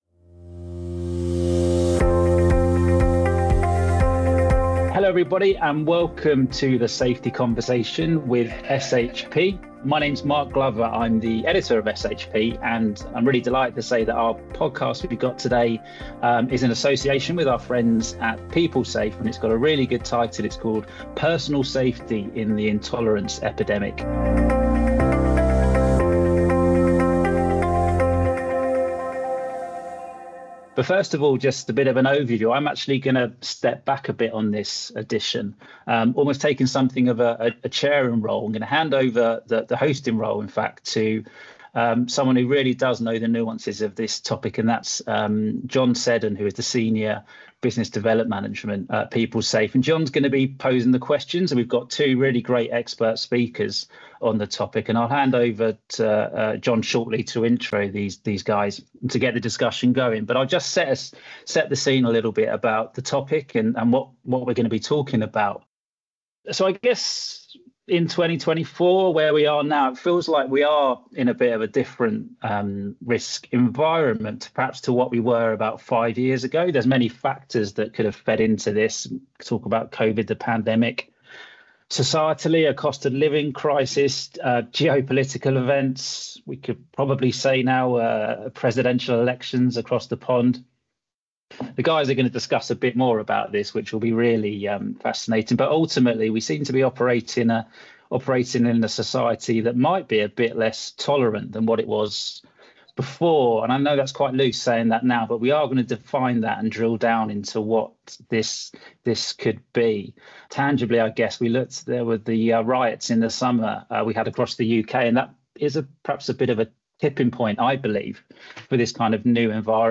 The Safety Conversation with SHP (previously the Health and Safety Podcast) aims to bring you the latest news, insights and legislation updates in the form of interviews, discussions and panel debates from leading figures within the profession.